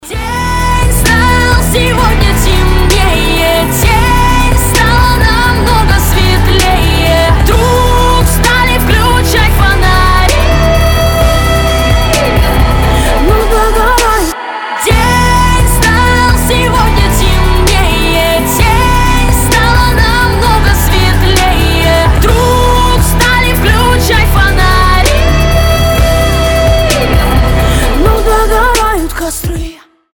• Качество: 320, Stereo
громкие
мелодичные
красивый женский вокал
сильный голос
поп-рок